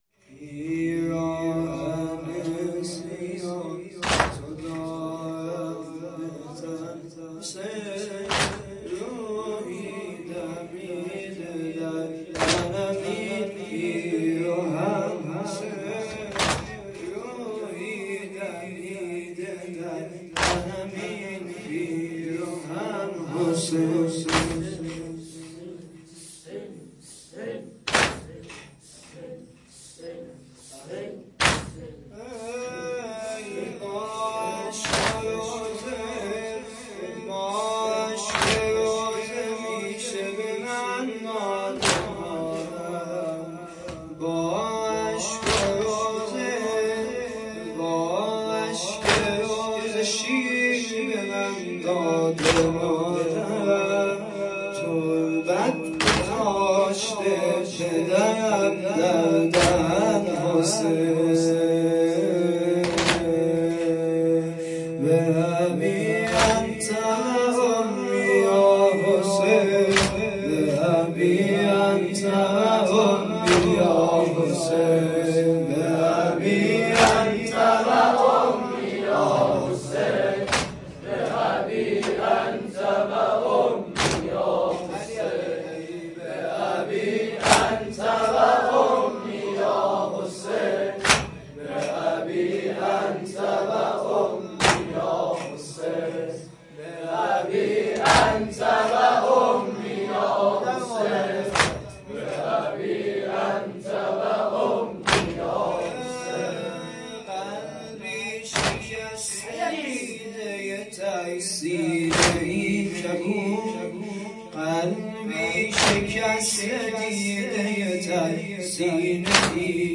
شب اول صفر 97